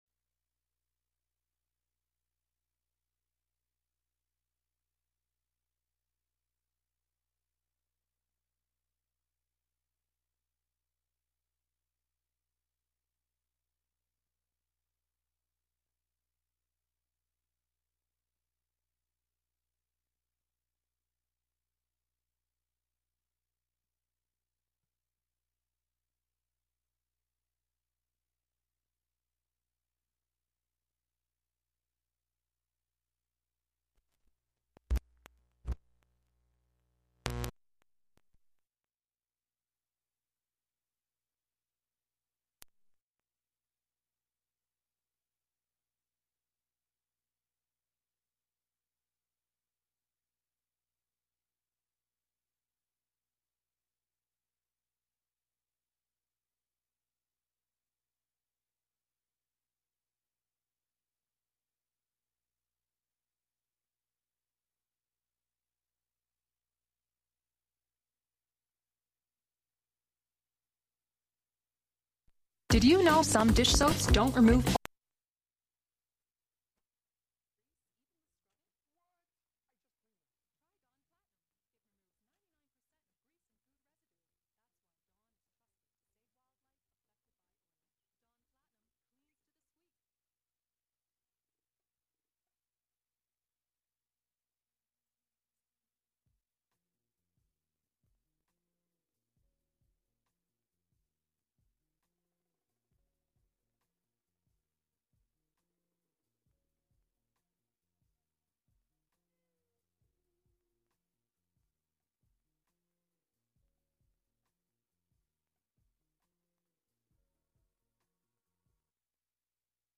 Prime Jive: Monday Afternoon Show- Live from Housatonic, MA (Audio)